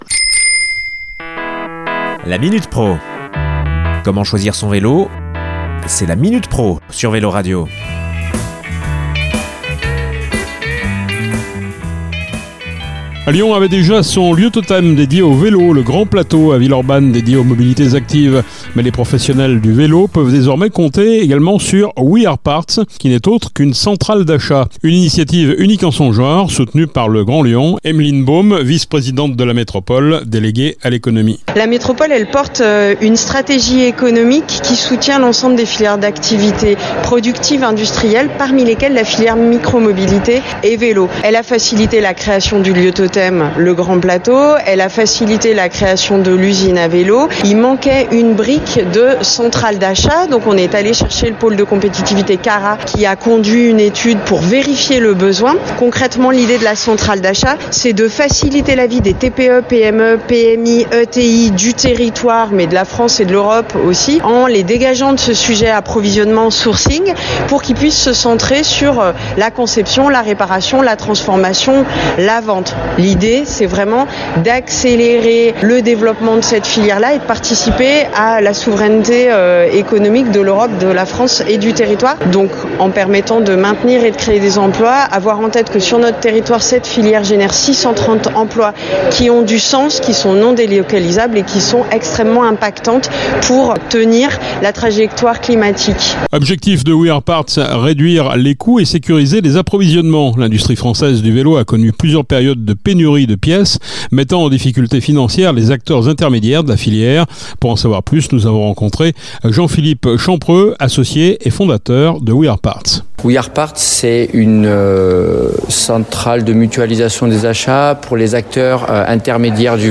Nous avons rencontré Emeline Baume, vice-présidente du Grand Lyon, en charge de l’Economie.